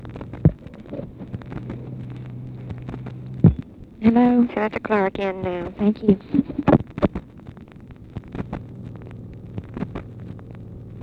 Conversation with OFFICE SECRETARY and OFFICE SECRETARY, June 12, 1964
Secret White House Tapes